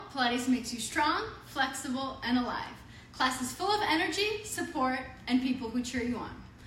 Noisy Sample: